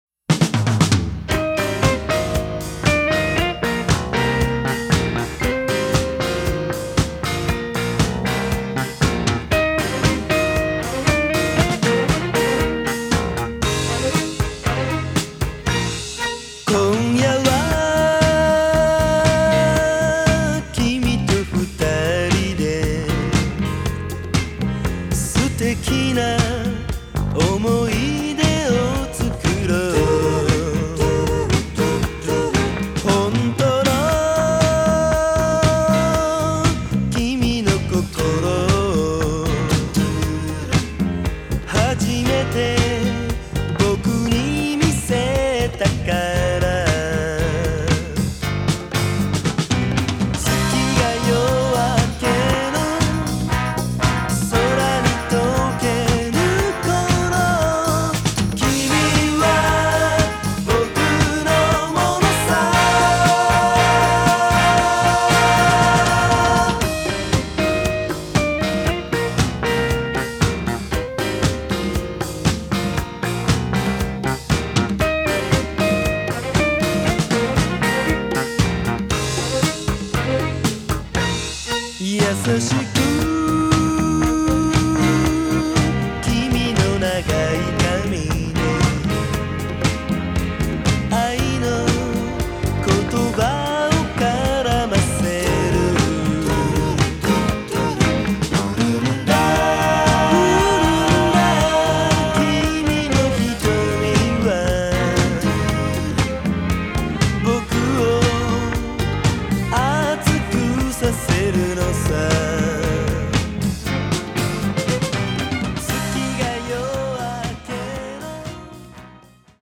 極上の和製フィリーソウル/クロスオーバーソウル
ジャンル(スタイル) JAPANESE POP / CITY POP